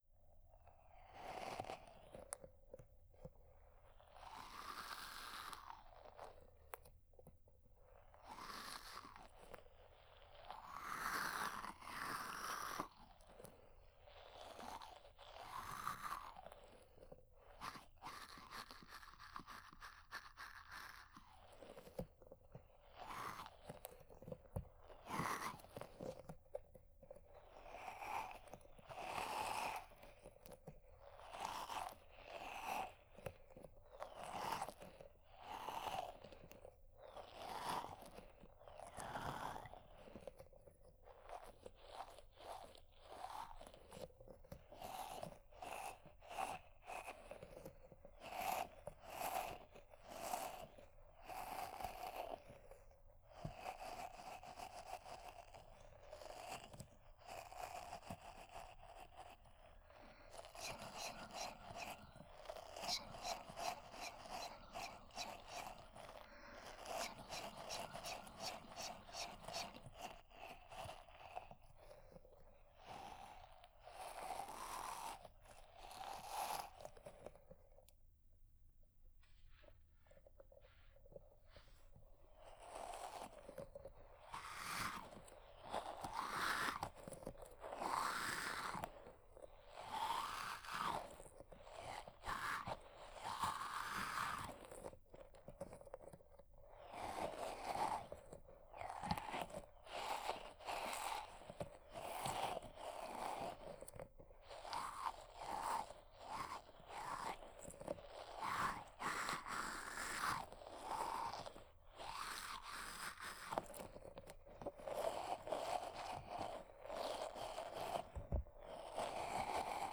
03.生活音パートのみ.wav